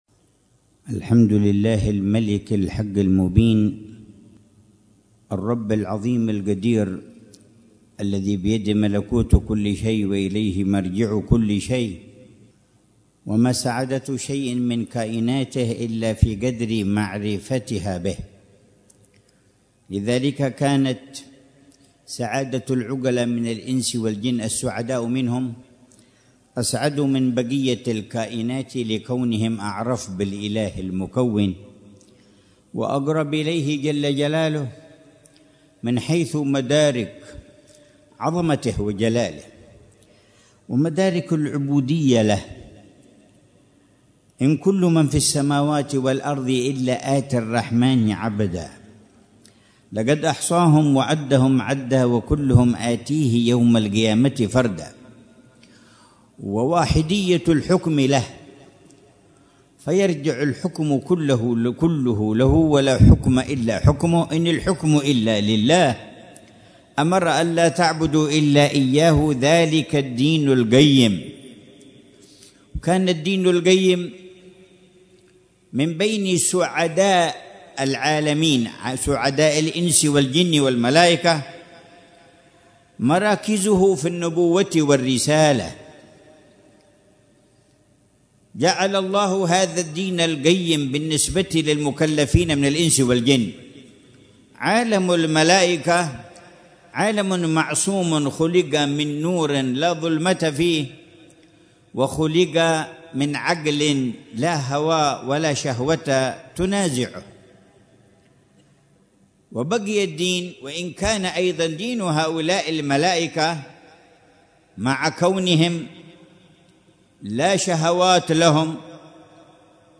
محاضرة العلامة الحبيب عمر بن محمد بن حفيظ في جلسة الجمعة الشهرية الـ62، في ساحة مقام الإمام عبد الله بن علوي الحداد، باستضافة حارتي الحاوي وحصن عوض بمدينة تريم، ليلة السبت 8 صفر الخير 1447هـ، بعنوا